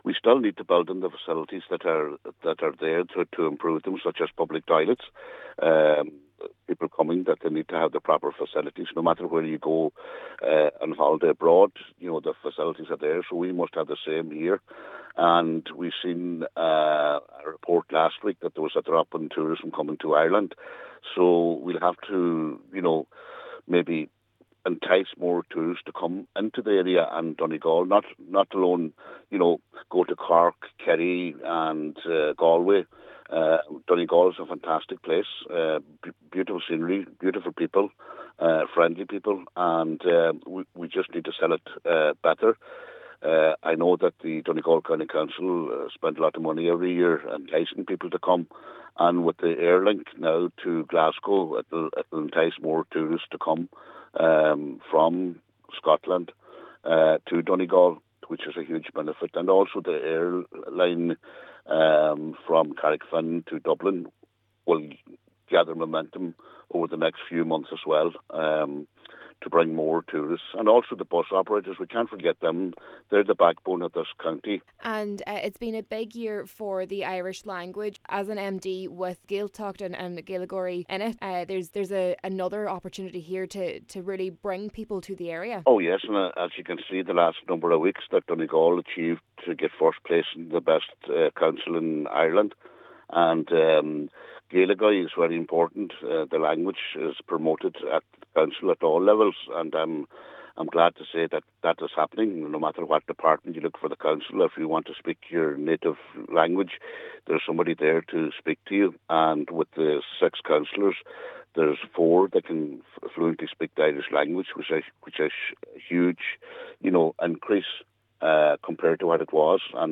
Cllr O’Fearraigh says there is an added opportunity to bring people to the Gaeltacht areas in West Donegal: